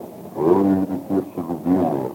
Диалектные особенности региона